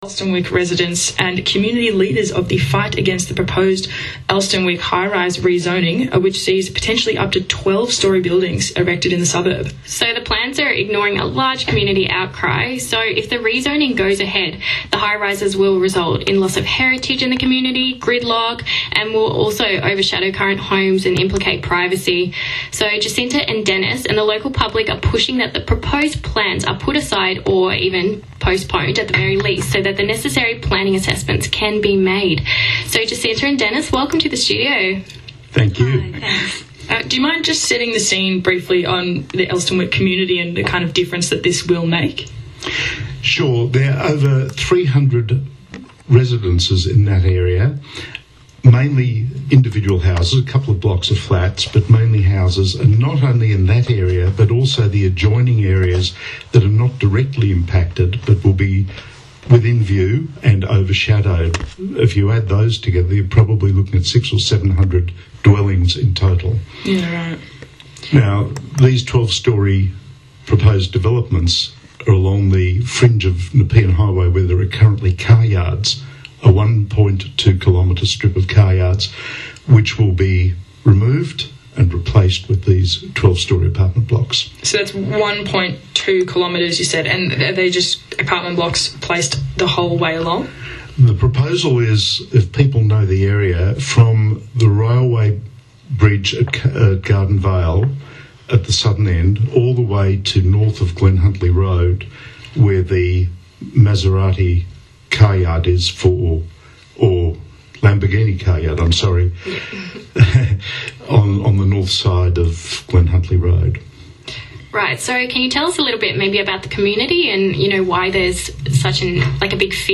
The following interview took place on Triple R yesterday morning.
elsternwick-rrr.mp3